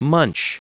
Prononciation du mot munch en anglais (fichier audio)
Prononciation du mot : munch